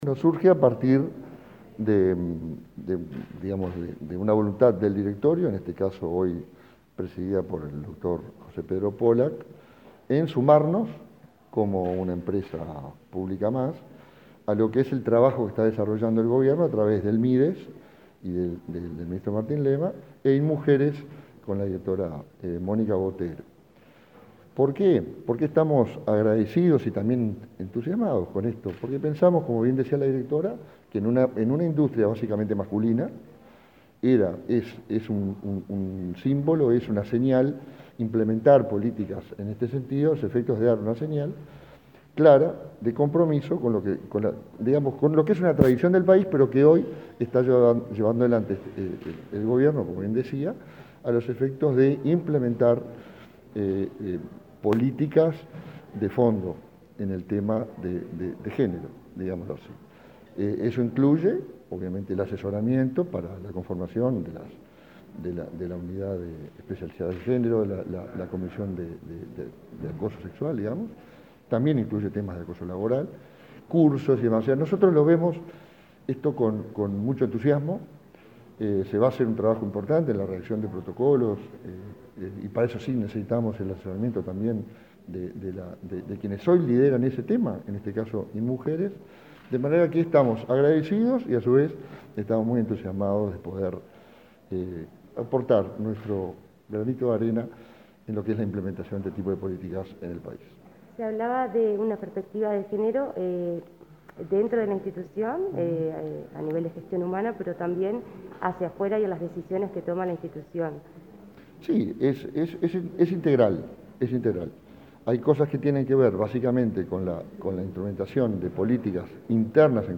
Entrevista al vicepresidente de AFE